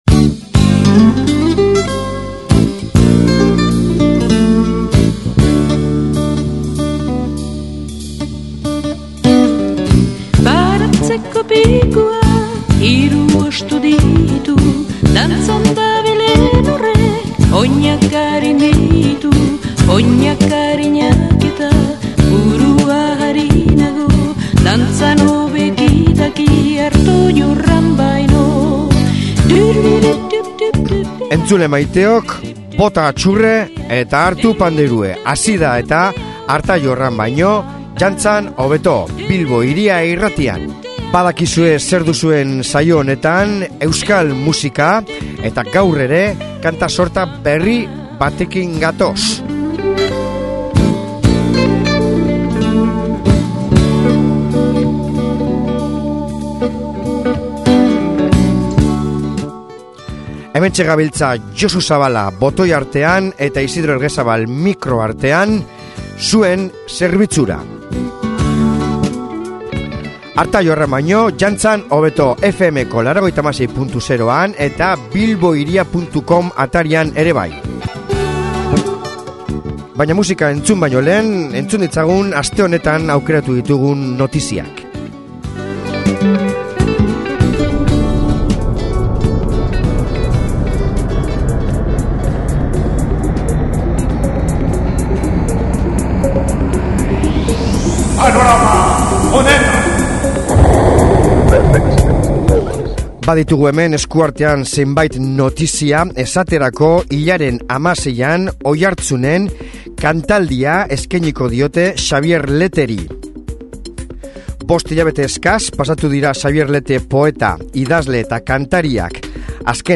Kanta sorta berri batekin gatoz, eta nahiko rockero!